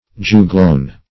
Search Result for " juglone" : The Collaborative International Dictionary of English v.0.48: Juglone \Ju"glone\, n. [L. juglans the walnut + -one.]